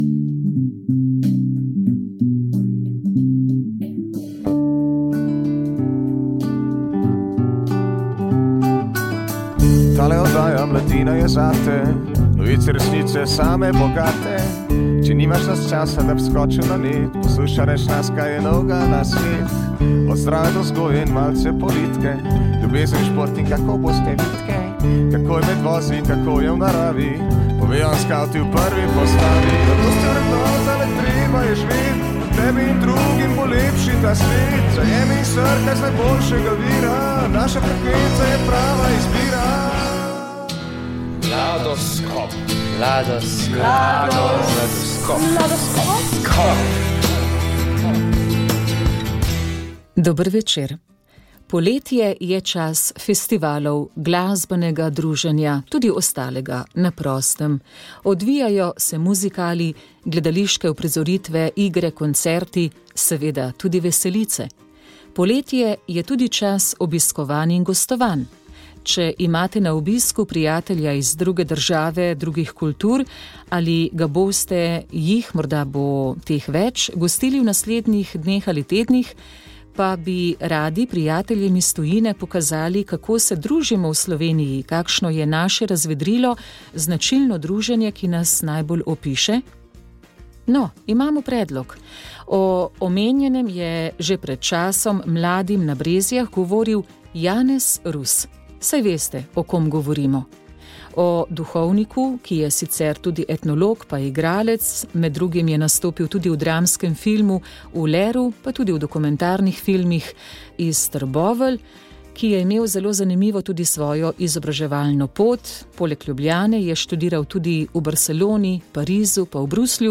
V Mladoskopu smo zbrali nekaj najodmevnejših pogovorov z Informative 2019, katerih rdeča nit je spodbuditi k raziskovanju in pridobivanju informacij za uspešnejše kreiranje poklicne poti.